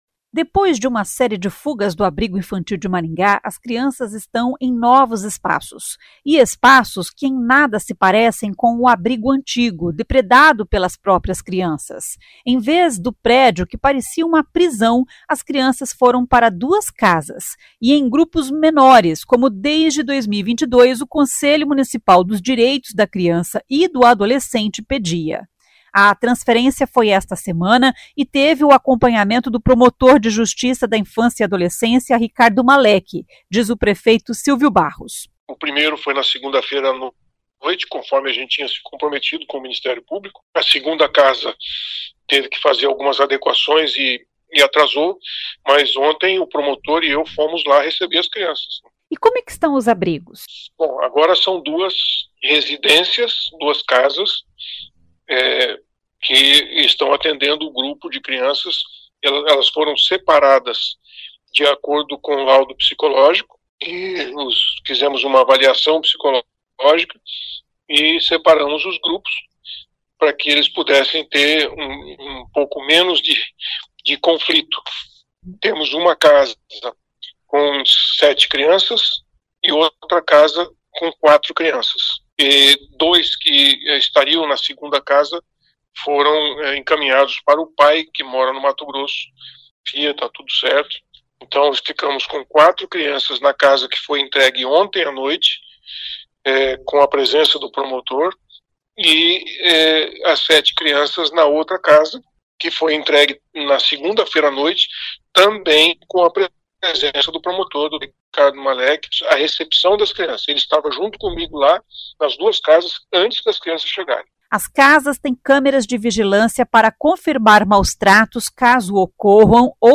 A transferência foi esta semana e teve o acompanhamento do promotor de Justiça da Infância e Adolescência, Ricardo Malek, diz o prefeito Silvio Barros.